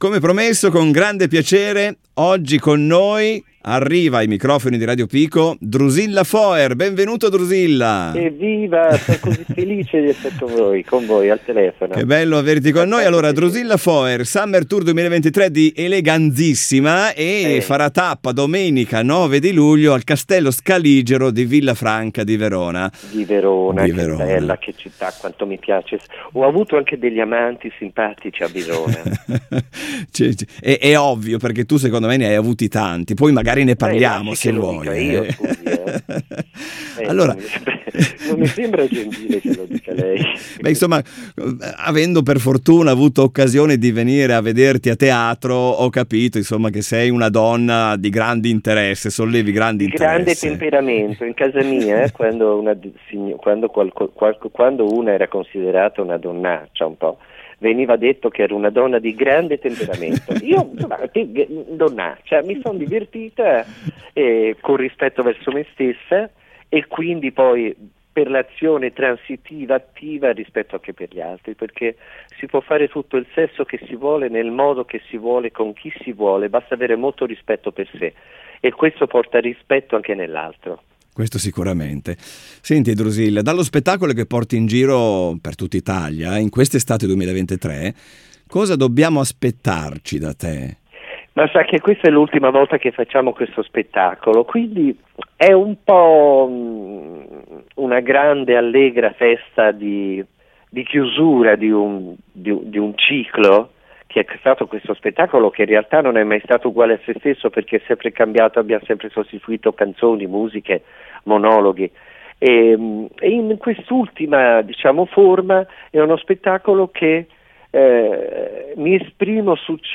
Radio Pico intervista Drusilla Foer
Al microfono di Radio Pico una graditissima ospite, Drusilla Foer che domenica 9 luglio calcherà il palco del Villafranca Festival: